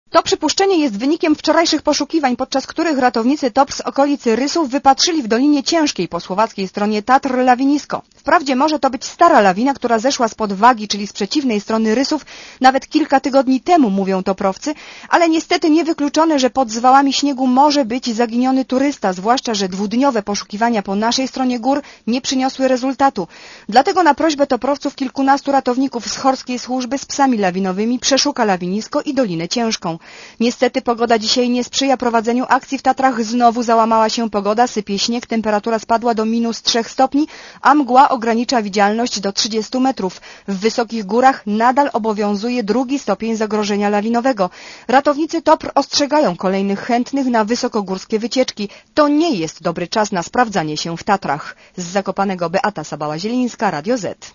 * Posłuchaj relacji reporterki Radia ZET (217 KB)*